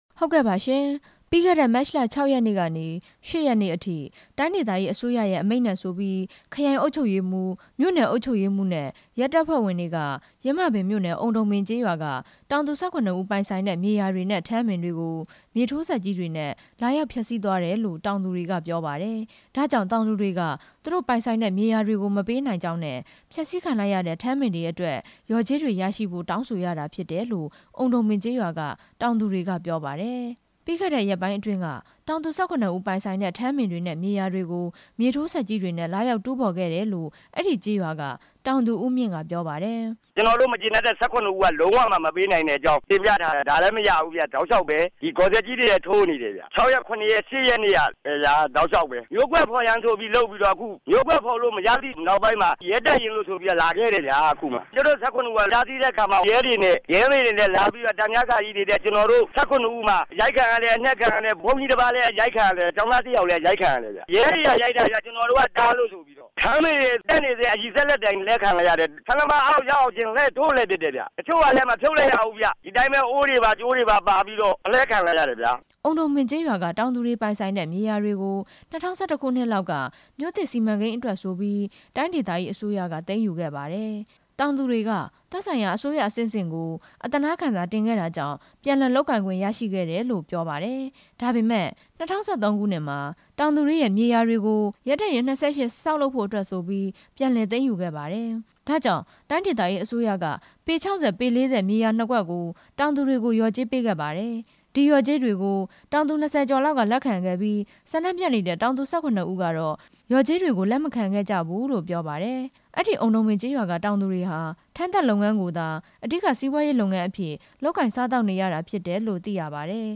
ဆန္ဒပြပွဲ အကြောင်း သတင်း ပေးပို့ချက်